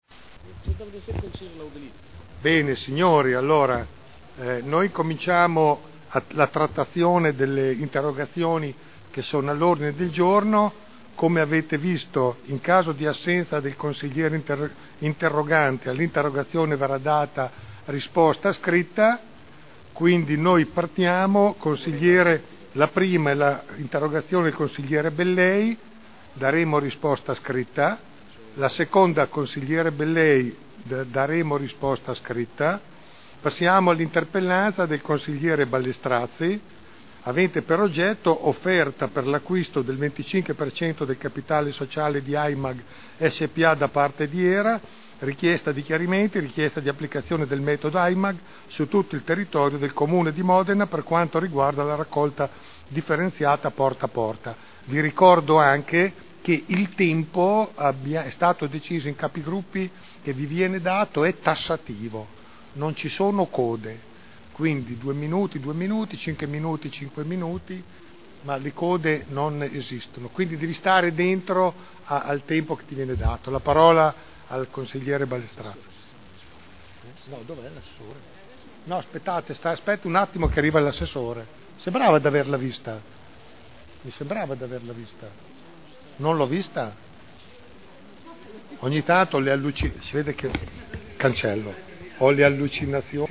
Il Presidente Giancarlo Pellacani apre la seduta per interrogazioni e interpellanze